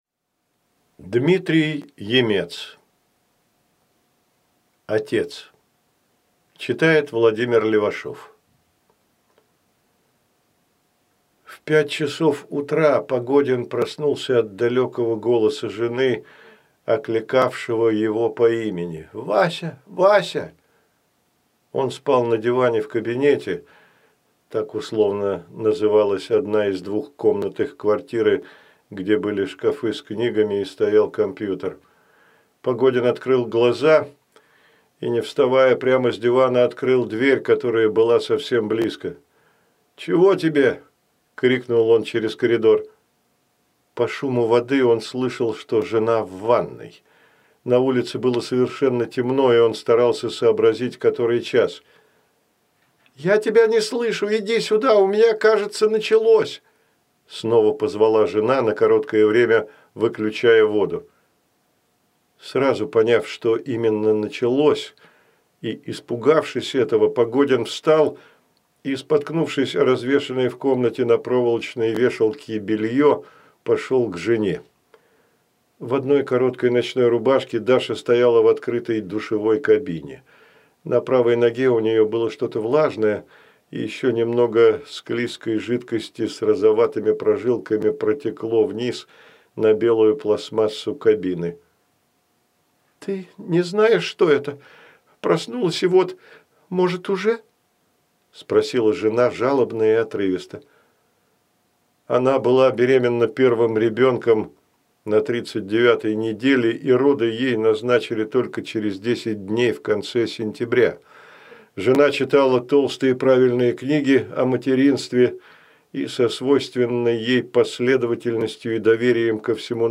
Аудиокнига Отец | Библиотека аудиокниг